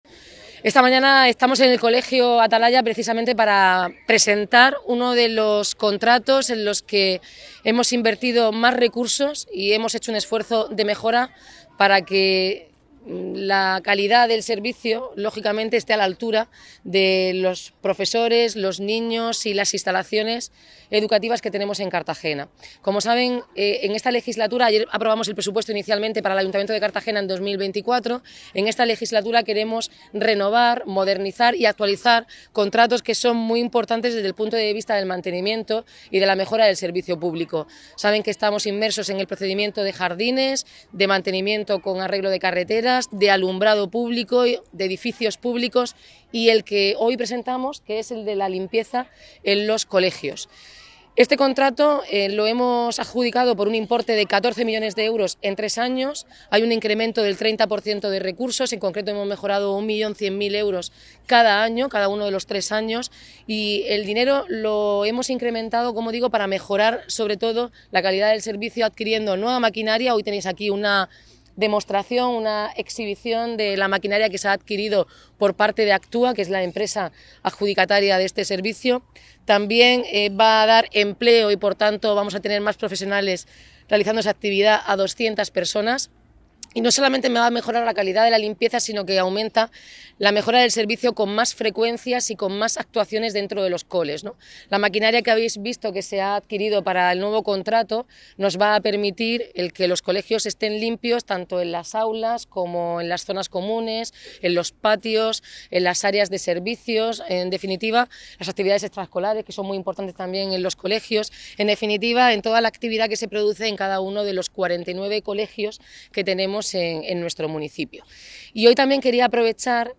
La alcaldesa Noelia Arroyo presenta el nuevo servicio de limpieza en colegios que sube un 30%, emplea a 200 personas, aumenta frecuencia de limpieza e incorpora maquinaria moderna
El acto de presentación del nuevo servicio de limpieza en colegios se ha realizado en las instalaciones del CEIP La Atalaya